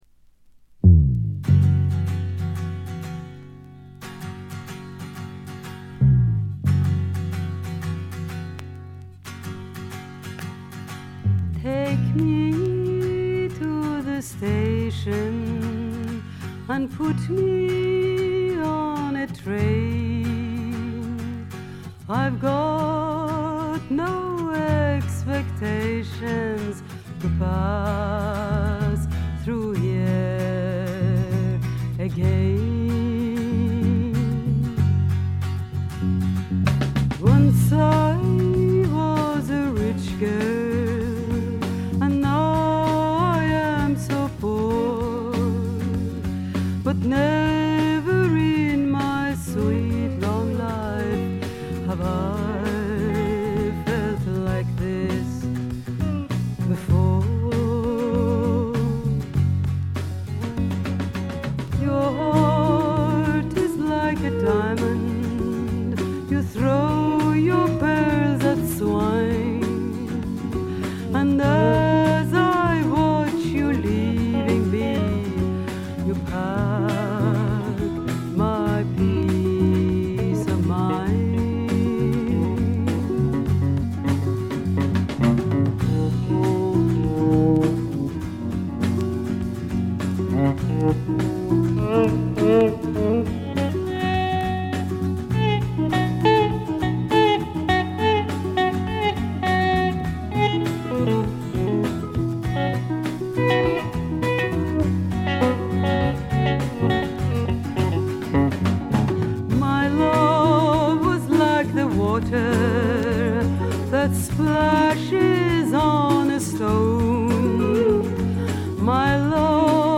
静音部でのバックグラウンドノイズ、軽微なチリプチ。
ともあれ、どんな曲をやってもぞくぞくするようなアルトヴォイスがすべてを持って行ってしまいますね。
試聴曲は現品からの取り込み音源です。